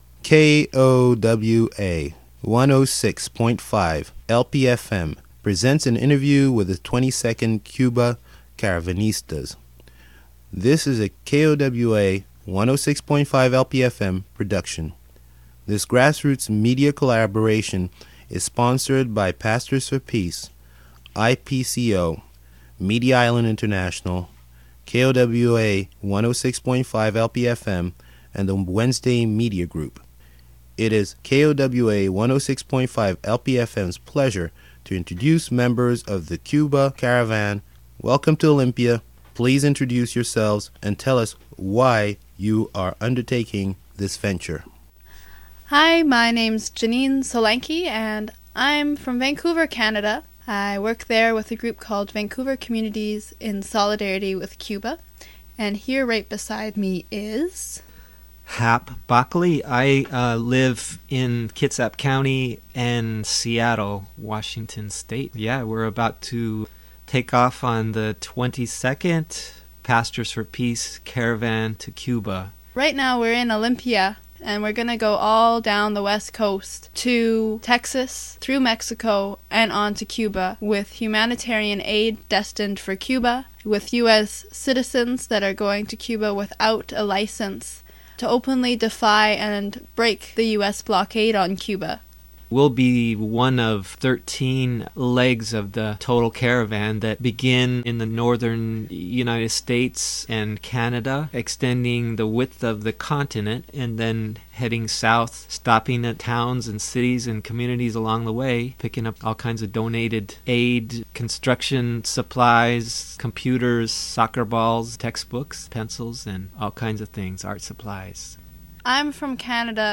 KOWA_1065_LP_FM_-_Pastors_for_peace_Cuba_caravan_interview.mp3